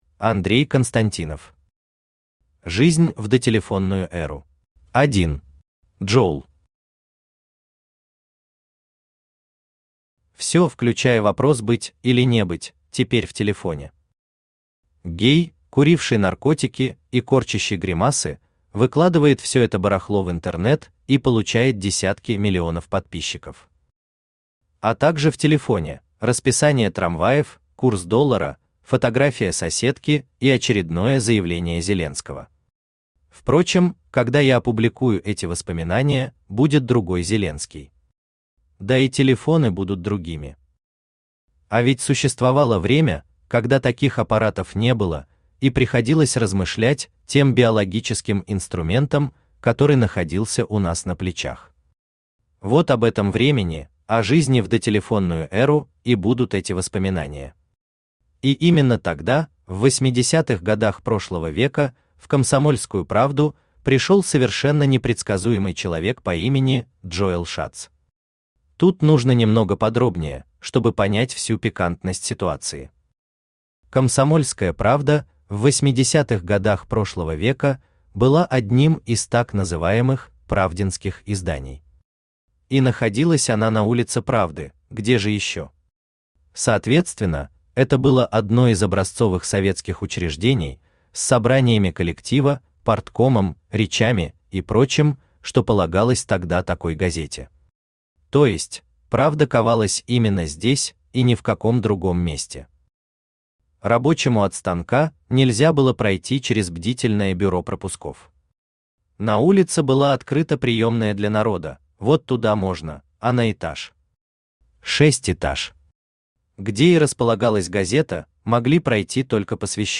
Аудиокнига Жизнь в дотелефонную эру | Библиотека аудиокниг
Aудиокнига Жизнь в дотелефонную эру Автор Андрей Константинов Читает аудиокнигу Авточтец ЛитРес.